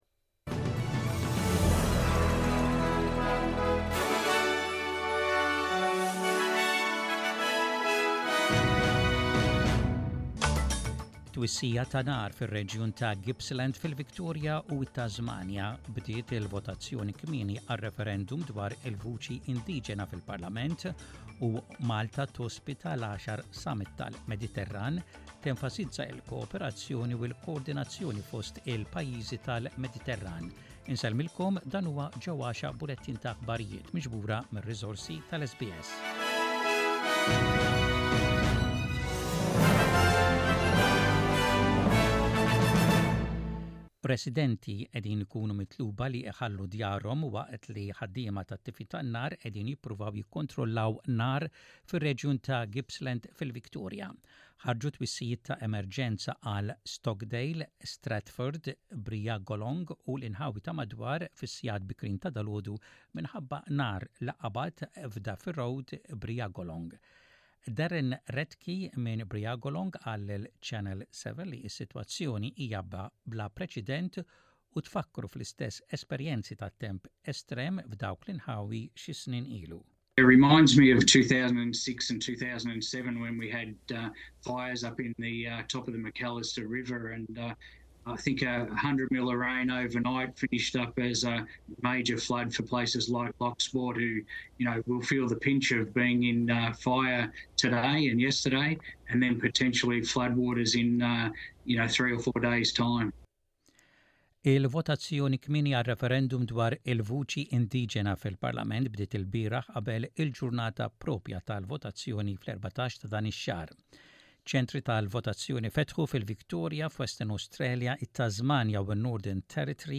SBS Radio | Maltese News: 03.10.23